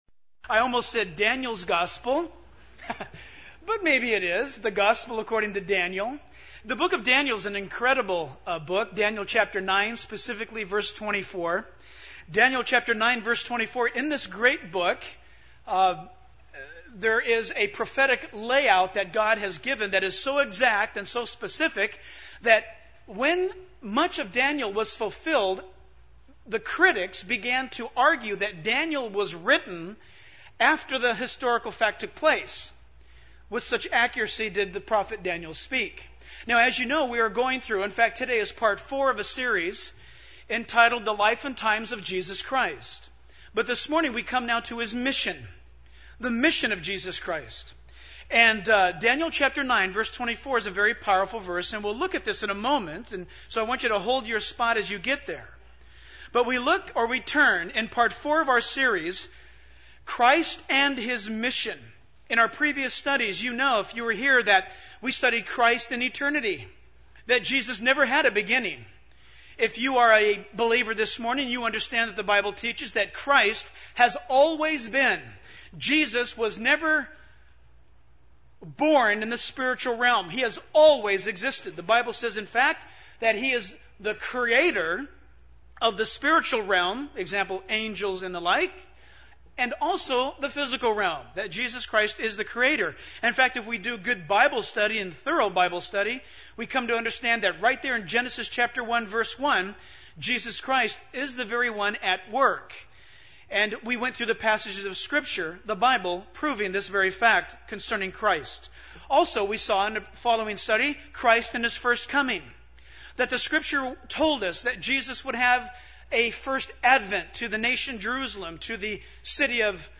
In this sermon, the speaker emphasizes the importance of having a mission in life, just like Jesus had a mission when he came into the world.